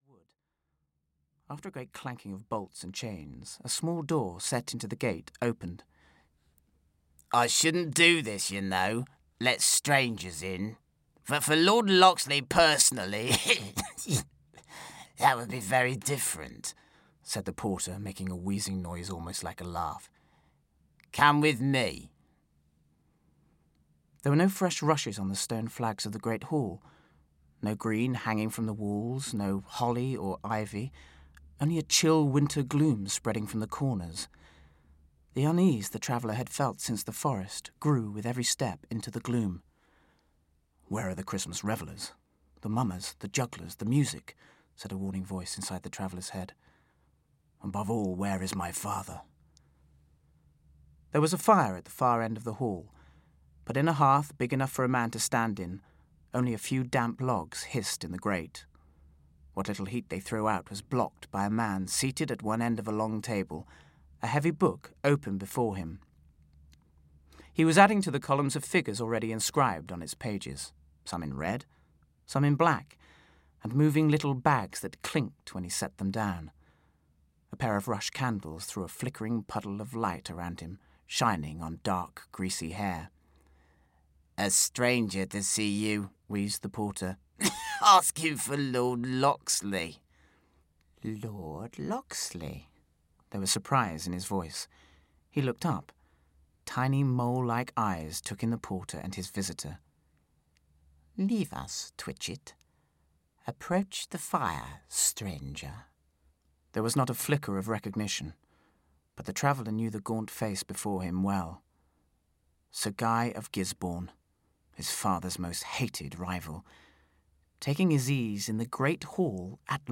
Audio knihaRobin Hood (EN)